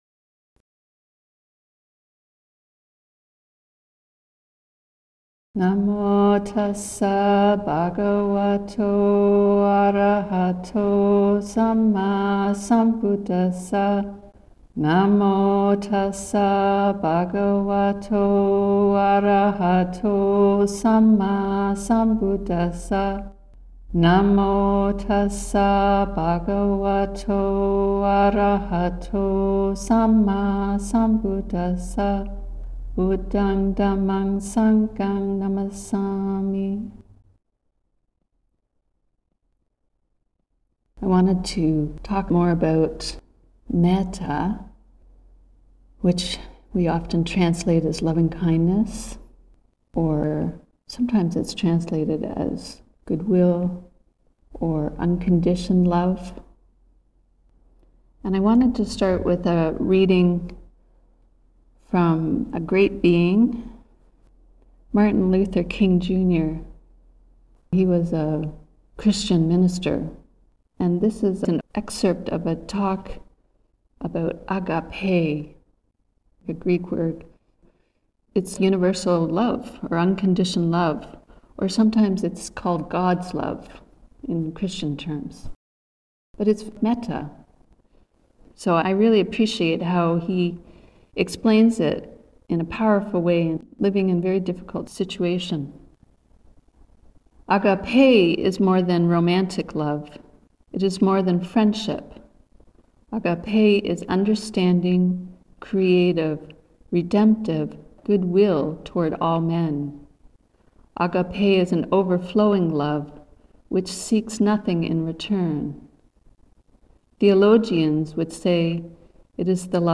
Ottawa Buddhist Society, Friday, May 16, 2025